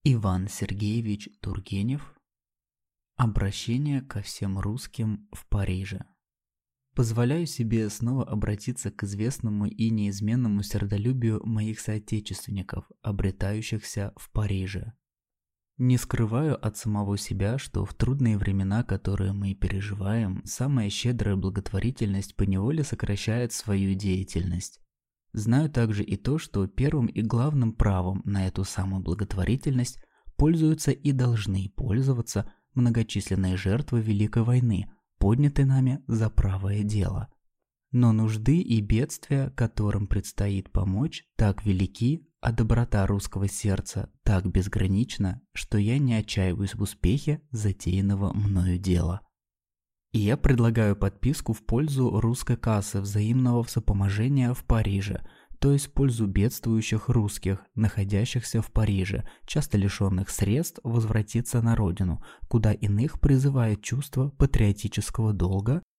Аудиокнига Обращение ко всем русским в Париже | Библиотека аудиокниг